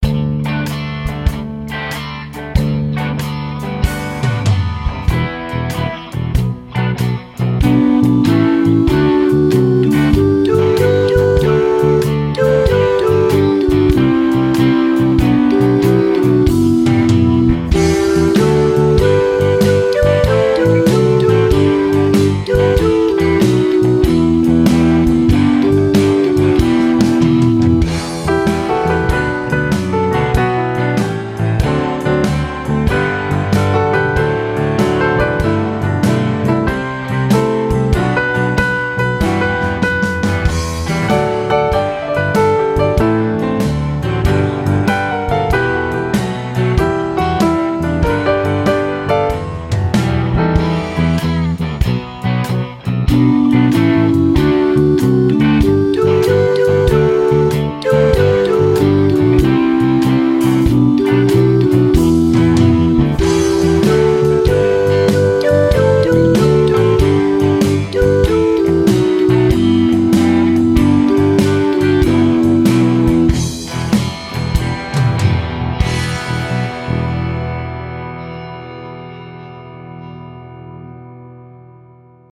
versions instrumentales